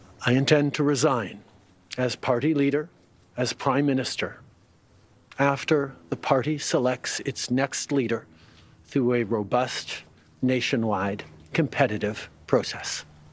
He made the announcement from his official residence, Rideau Cottage.